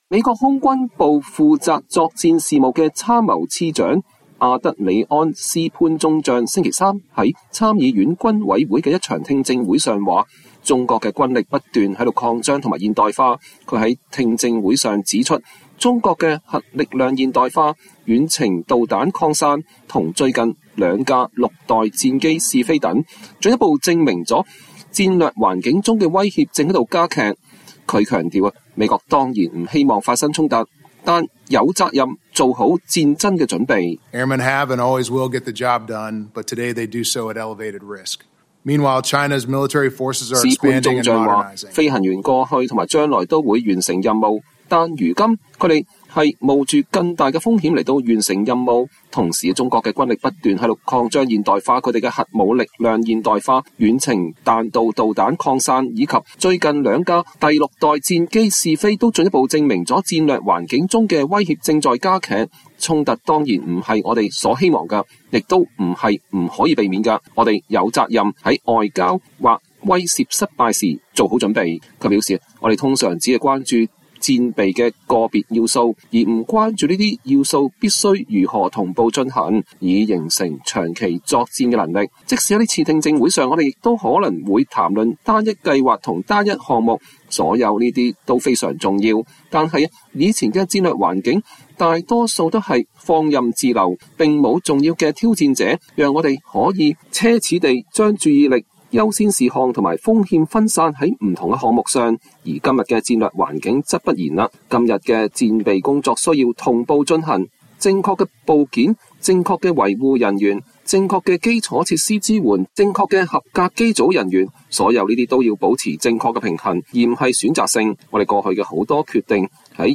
美國空軍部負責作戰事務的參謀次長阿德里安·斯潘中將(Adrian L. Spain)週三在參議院軍委會的一場聽證會上說，中國的軍力不斷在擴張和現代化。他在聽證會上指出，中國的核力量現代化、遠程導彈擴散和最近兩架六代戰機試飛等，進一步證明戰略環境中的威脅正在加劇。